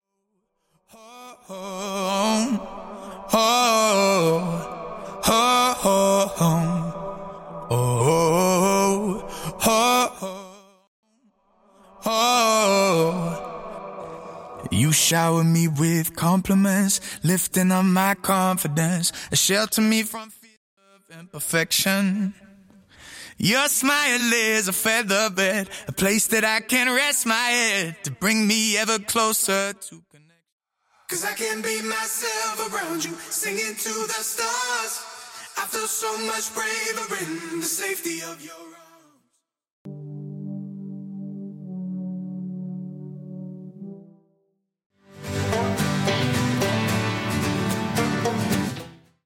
Studio Backing Vocals Stem
Studio Bassline Stem
Studio Guitars Stem
Studio Leading Vocals Stem
Studio Percussion & Drums Stem
Studio Synth 1 Stem